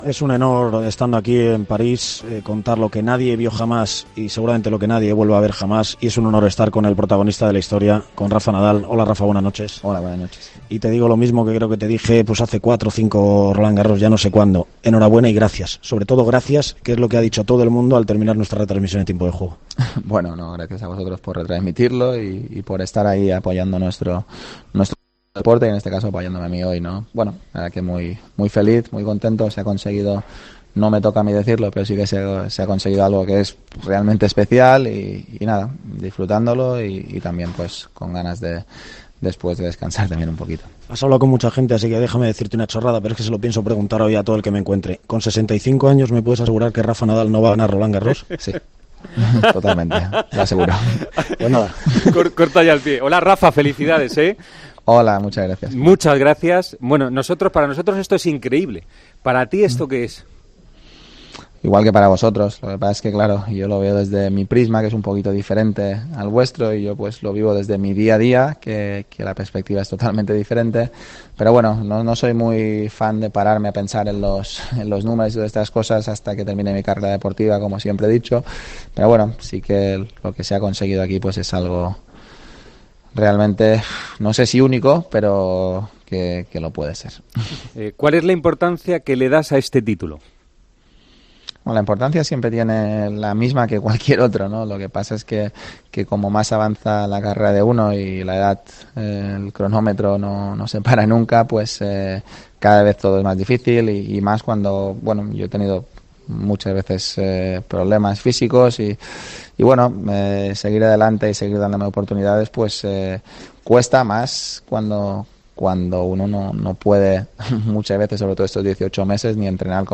Hablamos con el tenista español tras ganar su 12ª Roland Garros: "La Philippe Chatrier es el sitio más importante de mi carrera"
Con Paco González, Manolo Lama y Juanma Castaño